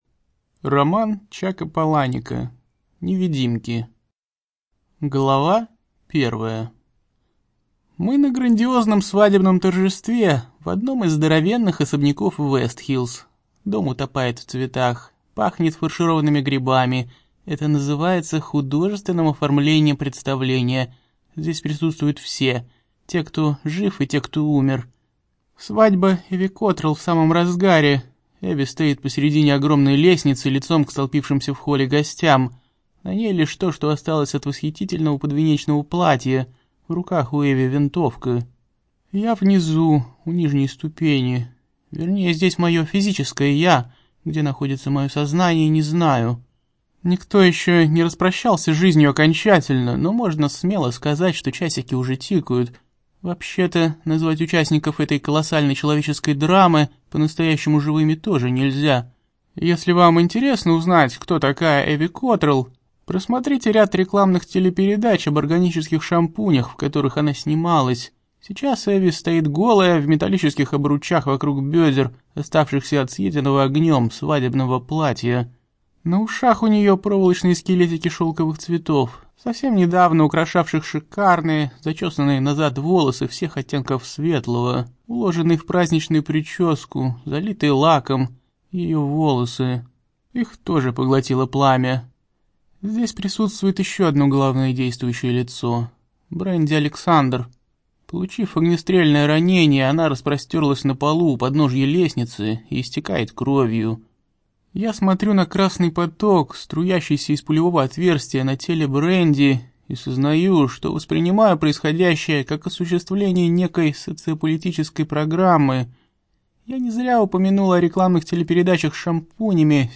Музыкальное сопровождение частично присутствует.